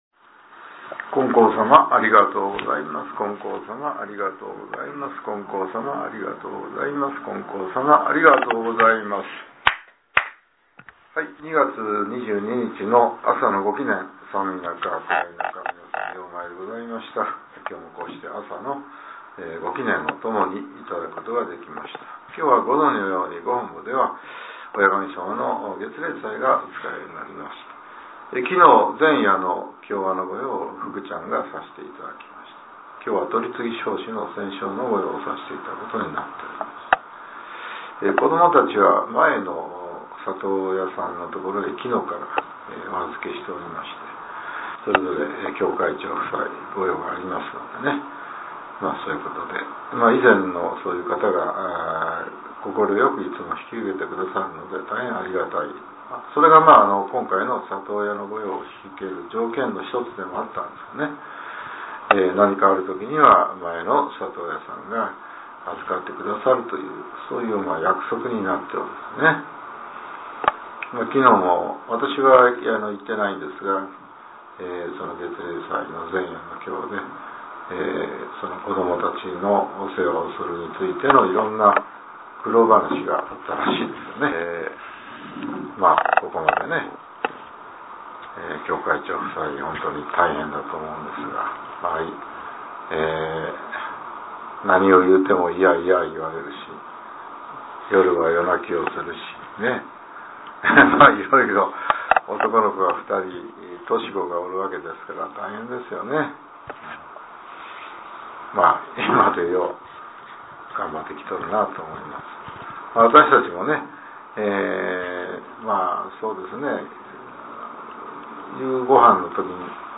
令和７年２月２２日（朝）のお話が、音声ブログとして更新されています。